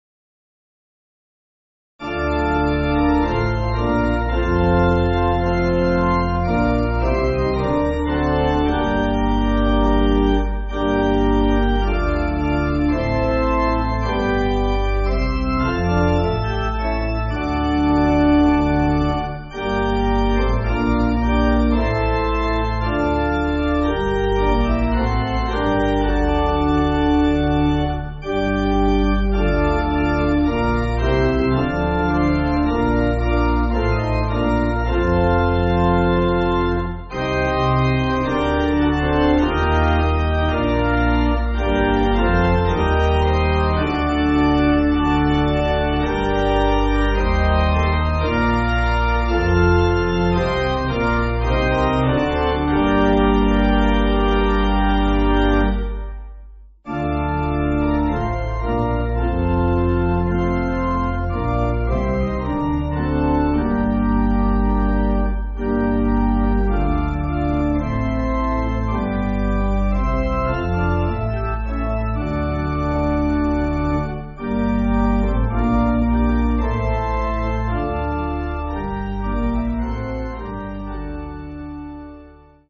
Organ
(CM)   5/Gm